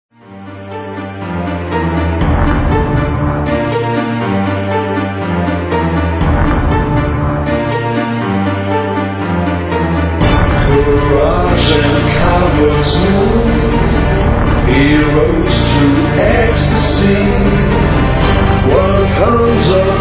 'dark neo-classical/industrial'